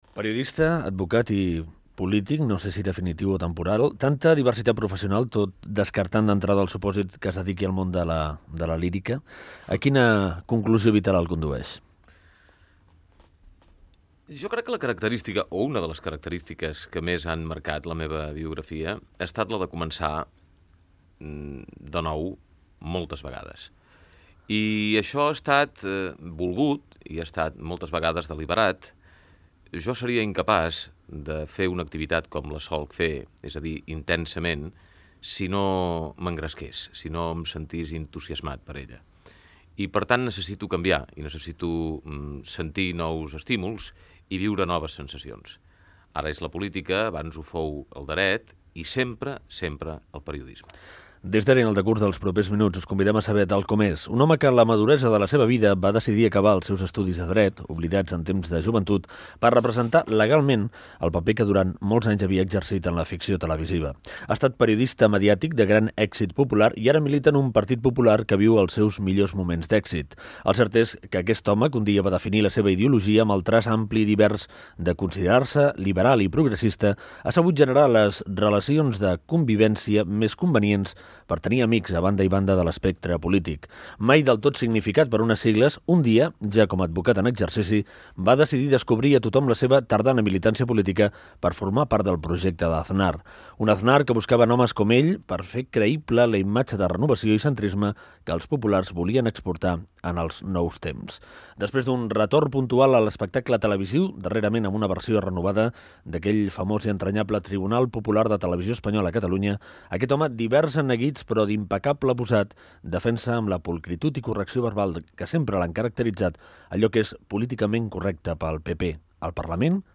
Fragment d'una entrevista al periodista, advocat i polític Ricard Fernández Deu.